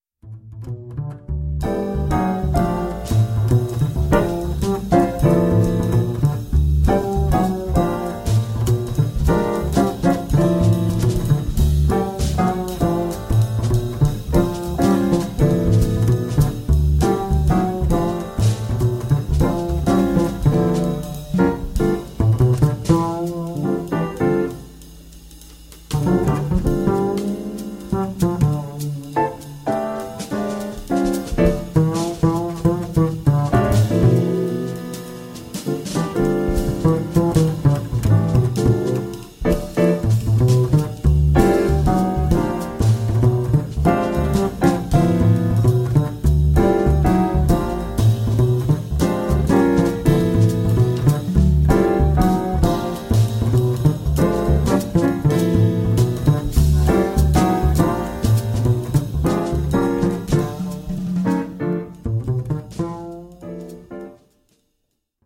pianoforte
contrabbasso
batteria
che alterna battute regolari ad una irregolare
in un ending funkeggiante sul tema iniziale.